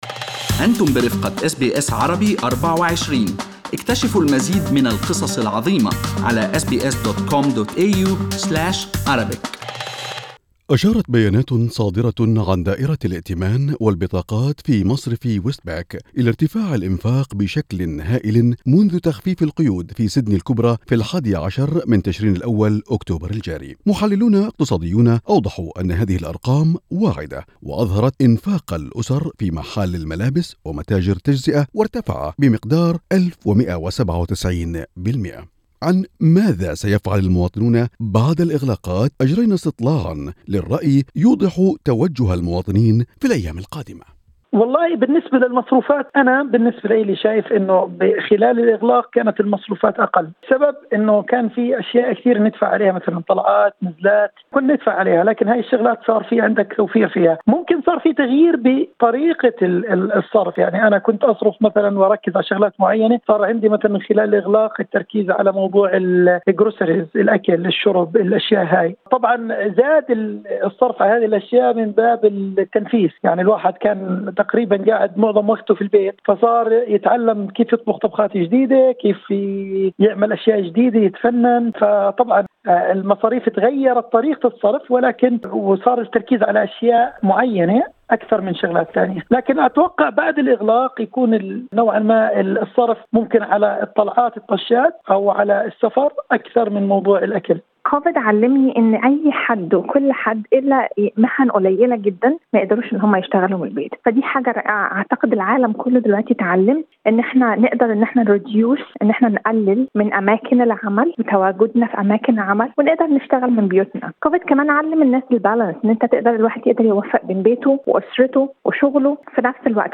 استطلاع لرأي أبناء الجالية بعد الإغلاق: وعي أكثر بالمصروفات واستمتاع بالحرية